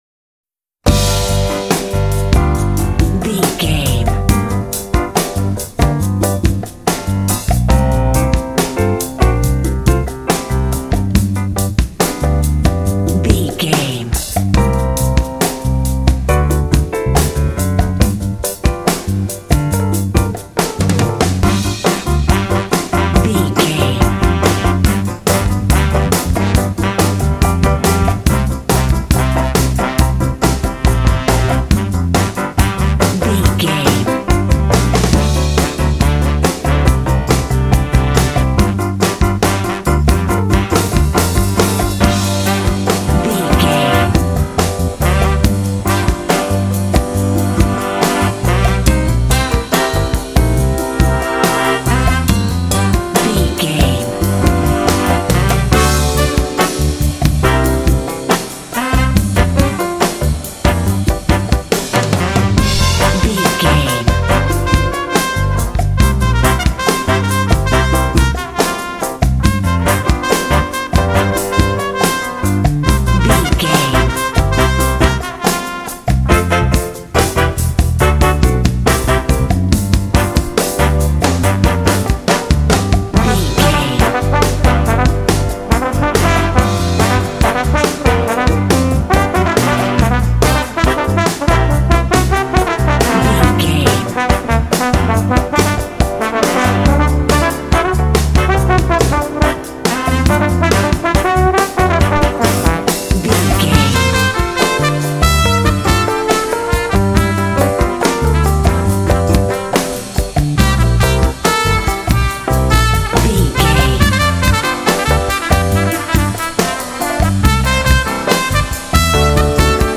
Aeolian/Minor
funky
groovy
bright
brass
drums
piano
bass guitar
trumpet
electric piano
jazz